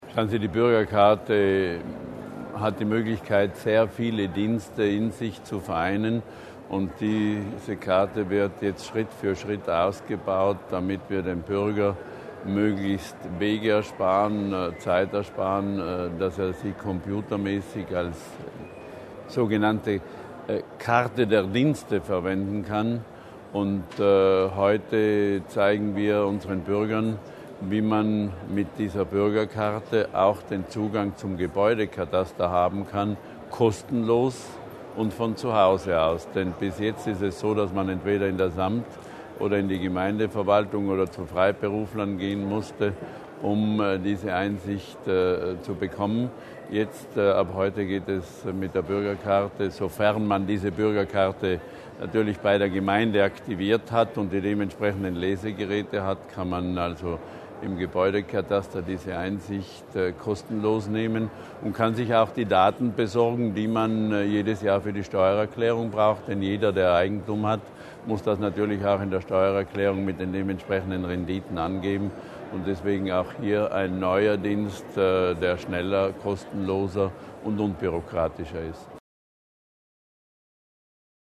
Landesrat Berger über die neuen Dienste des Kataster